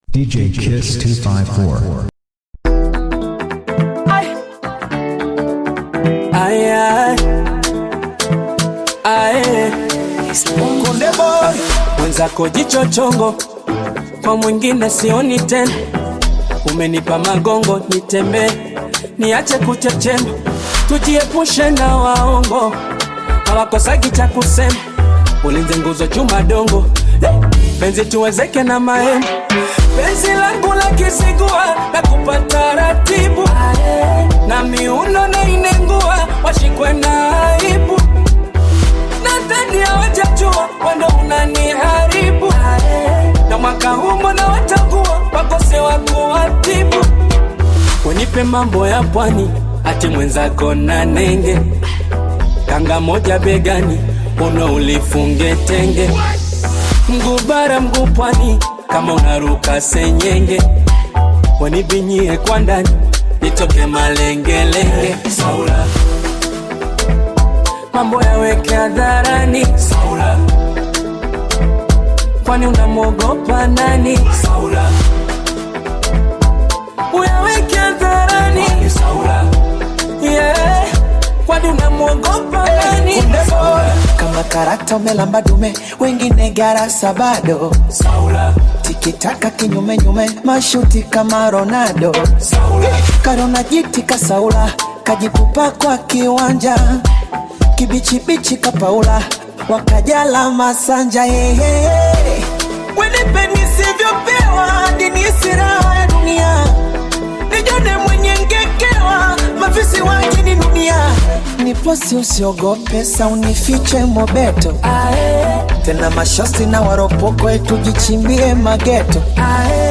DJ MIXTAPE